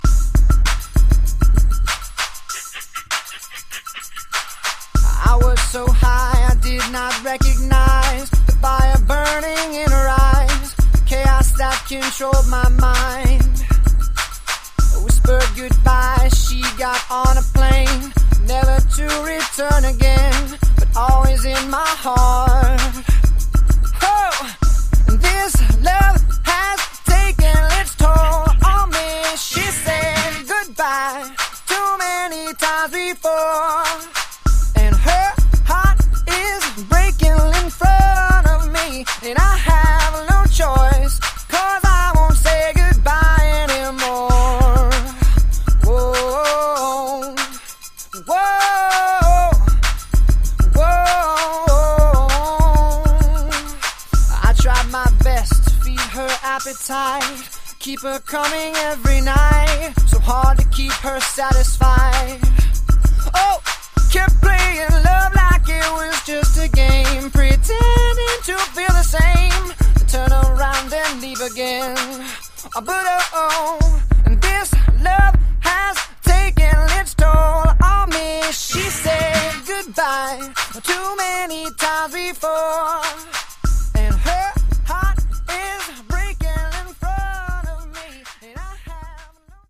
99 bpm